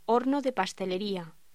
Locución: Horno de pastelería
voz